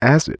Acid Spoken Word
SFX
yt_mzrUE96C4VY_acid_spoken_word.mp3